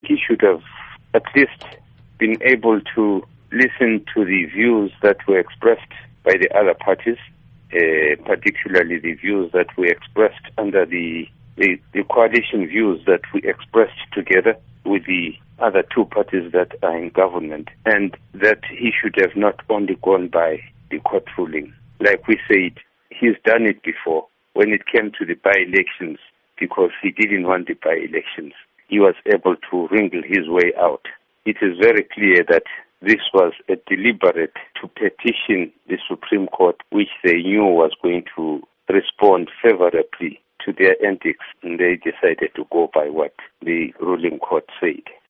Interview with Dumiso Dabengwa